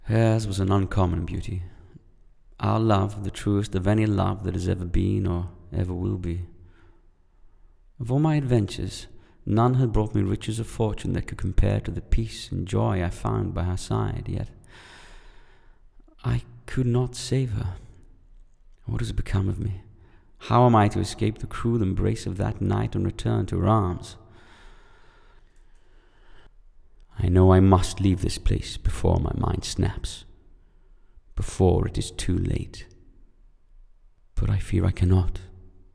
Voiceover sample